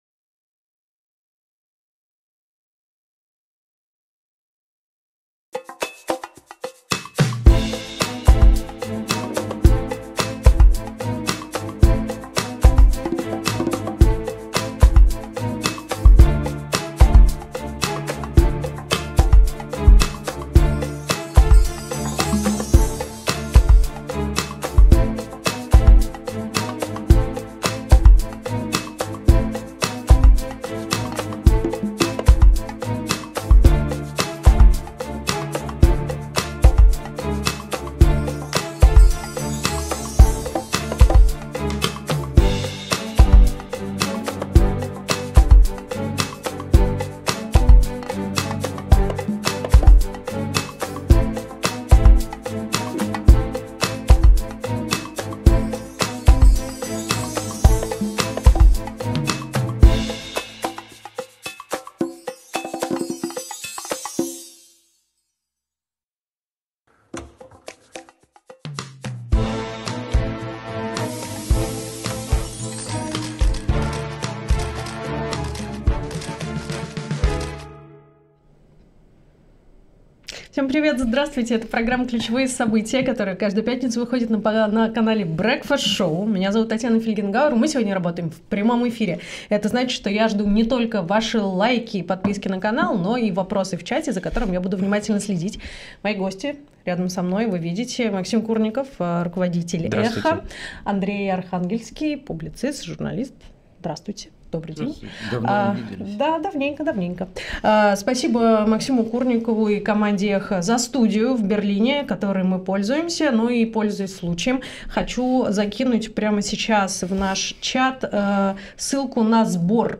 Ключевые события в прямом эфире.